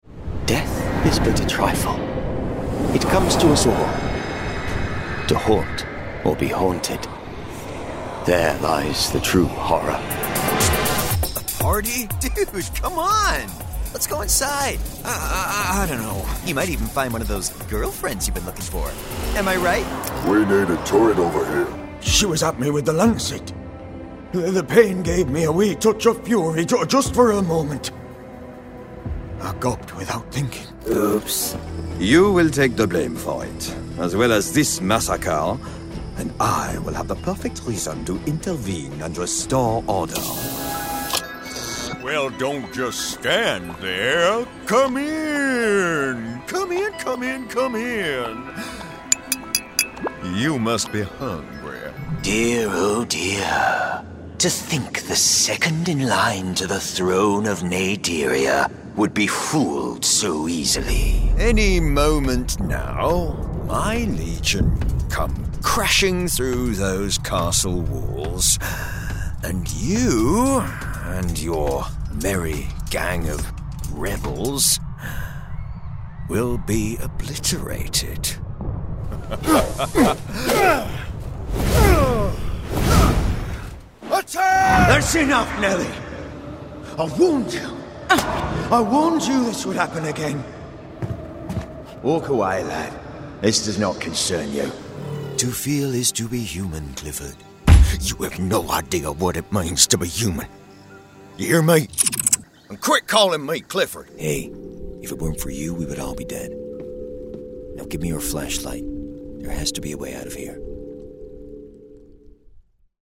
VIDEO GAME SHOWREEL
His deep, authentic RP voice lends youthful gravitas to commercials and narration, while his versatile accent range makes him a standout character actor for games, animation, and radio.
Male
British RP